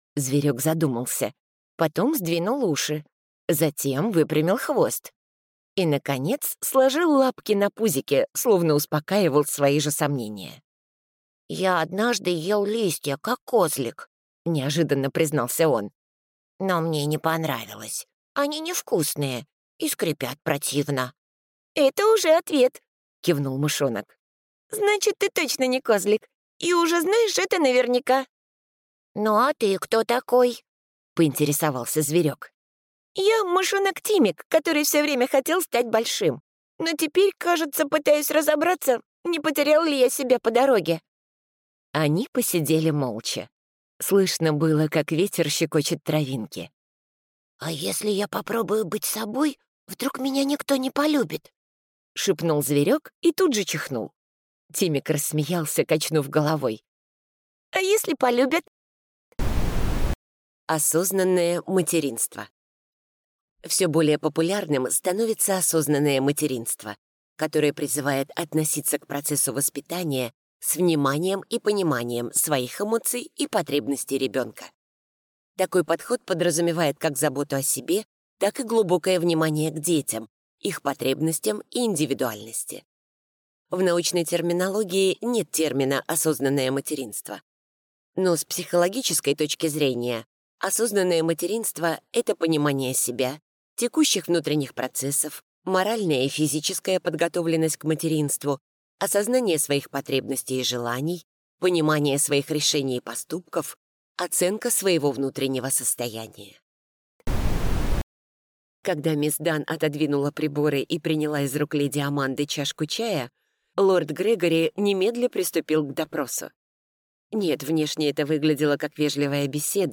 Запись с дикторами Rideró